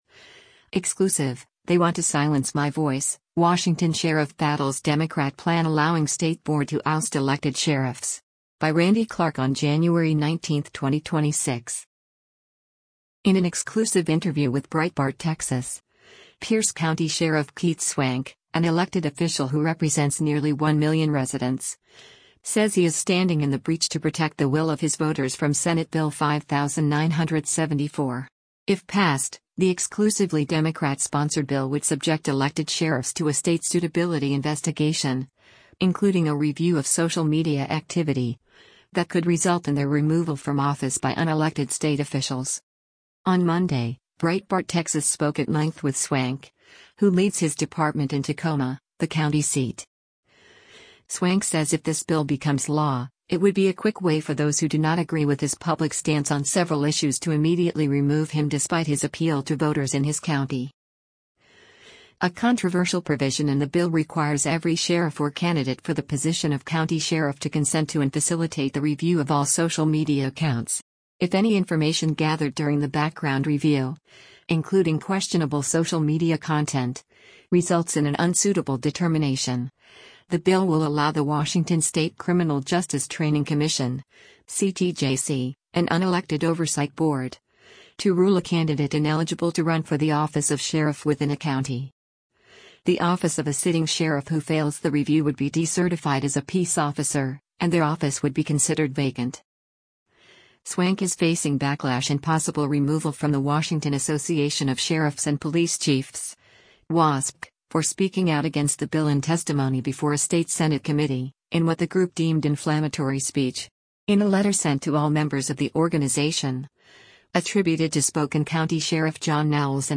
In an exclusive interview with Breitbart Texas, Pierce County Sheriff Keith Swank, an elected official who represents nearly one million residents, says he is “standing in the breach” to protect the will of his voters from Senate Bill 5974.